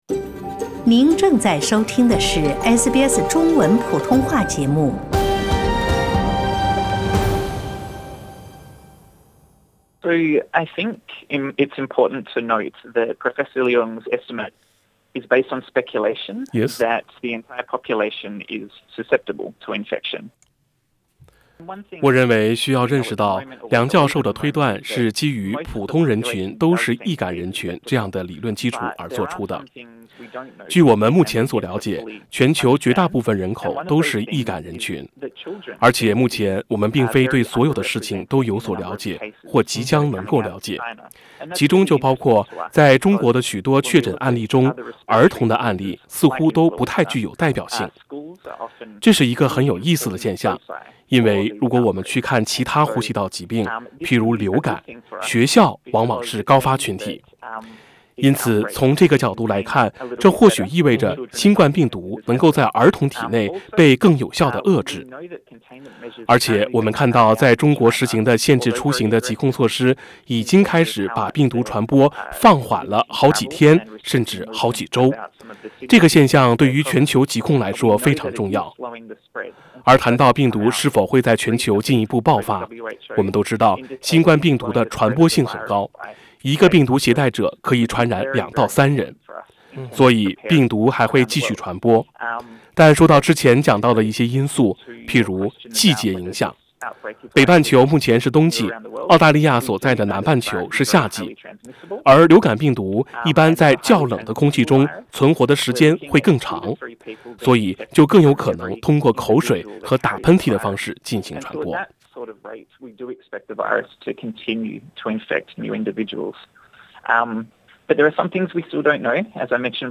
點擊文首圖片收聽寀訪錄音。